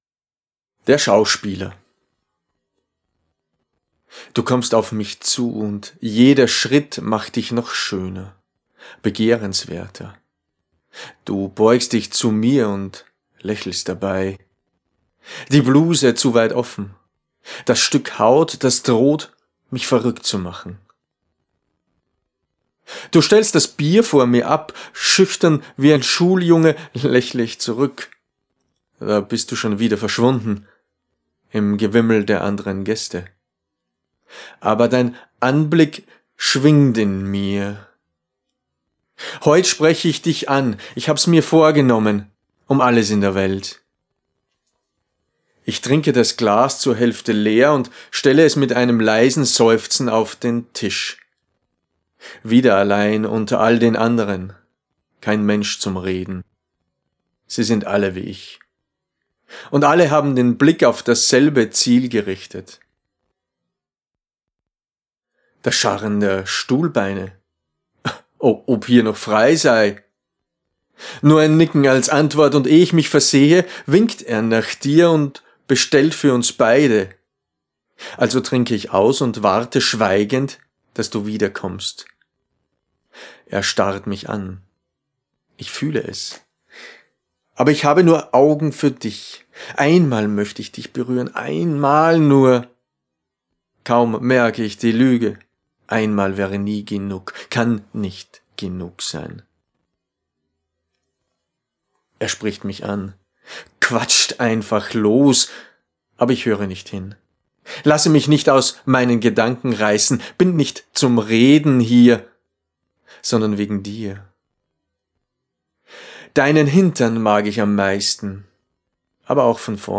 Erzählungen - Eine Auswahl